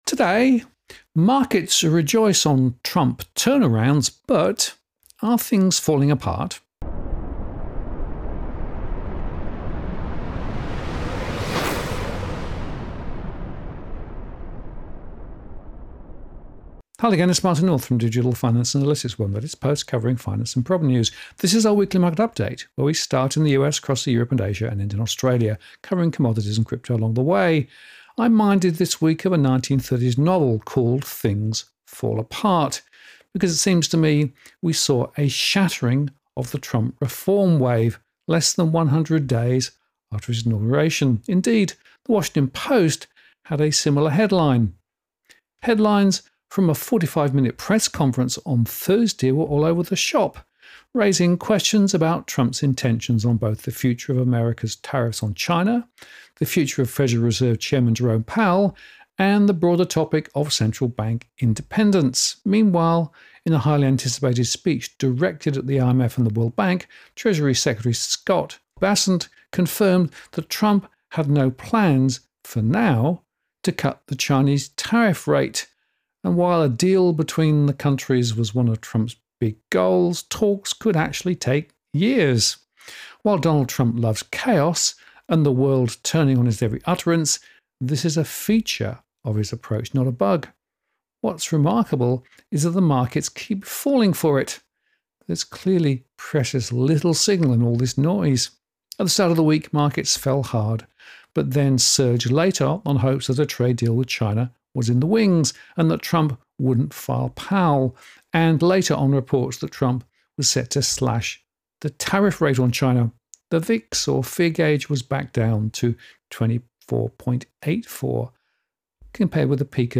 DFA Live Q&A Replay